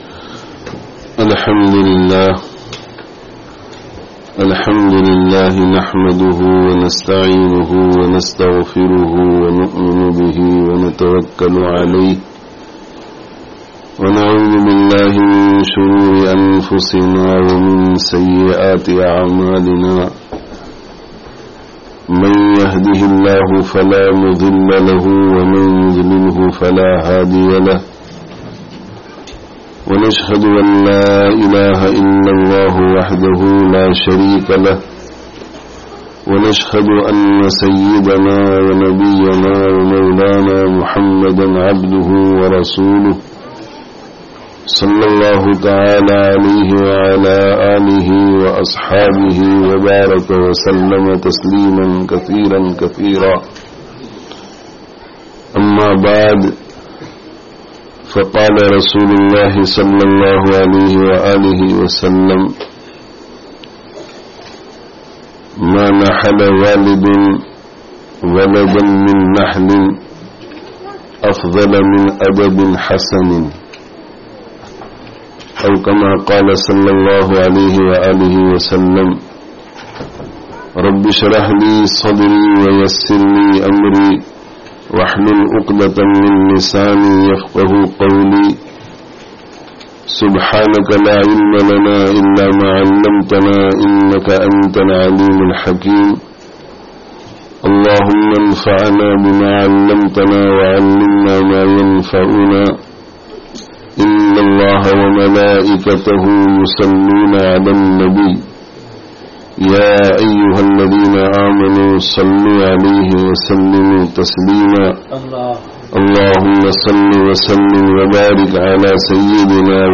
Ta'līm awr Tarbiyat [Completion of the Qur'an] (AMI Centre, La Courneuve, France 23/06/19)